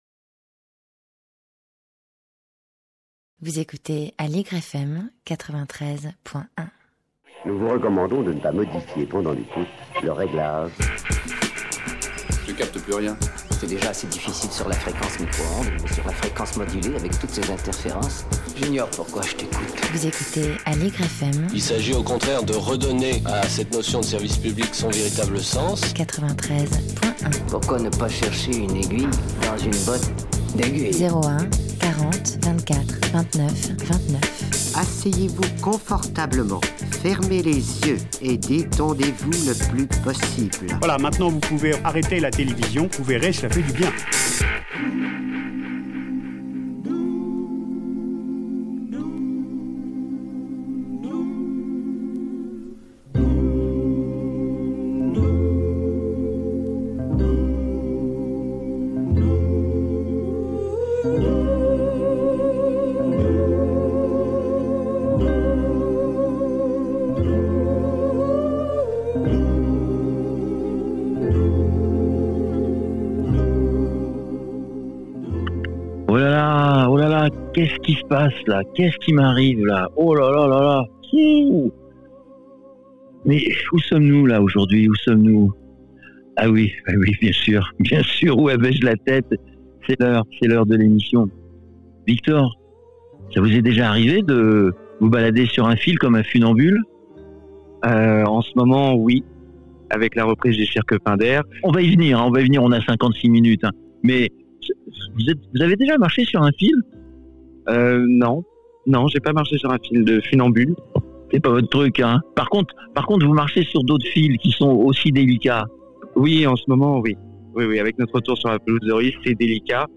L’étincelle dans la ville est allé à sa rencontre à Paris au milieu du cirque Pinder et dans ses coulisses, avec ses artistes.